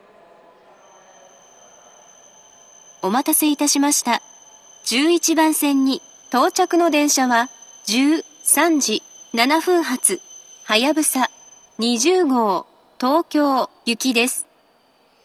１１番線到着放送